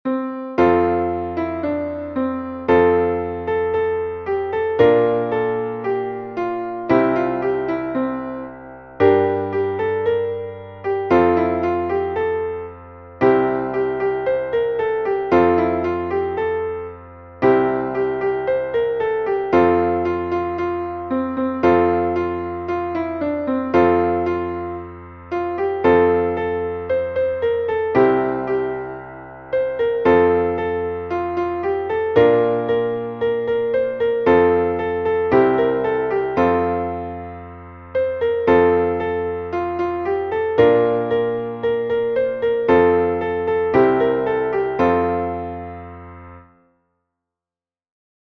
μελωδία και συγχορδίες, Fmaj